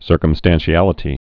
(sûrkəm-stănshē-ălĭ-tē)